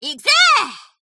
贡献 ） 协议：Copyright，其他分类： 分类:伏特加(赛马娘 Pretty Derby)语音 您不可以覆盖此文件。